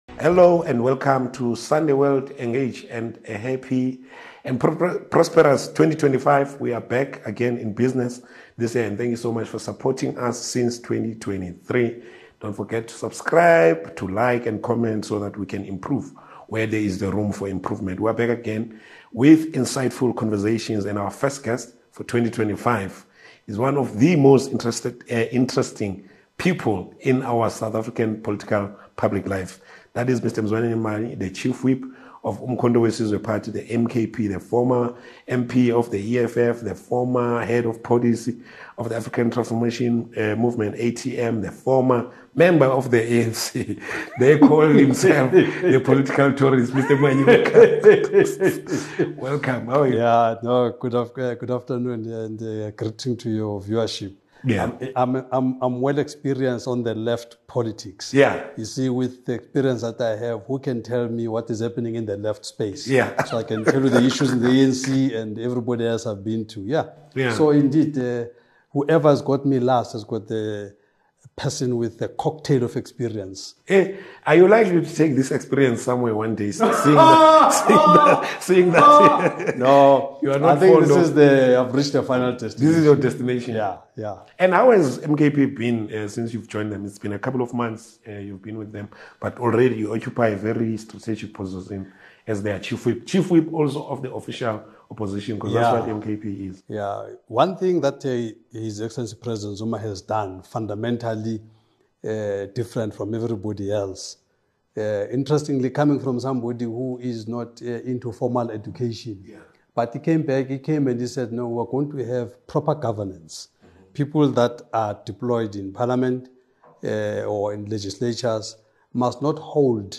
Mzwanele Manyi Opens Up on Zondo Report, ANC, EFF, and His Role as MK Party Chief Whip In this exclusive interview, Mzwanele Manyi shares his insights on the Zondo Commission report, his views on the ANC and EFF, and the key responsibilities he holds as the Chief Whip of the MK Party. Join us for a candid discussion on South African politics, accountability, and the future of leadership in the country.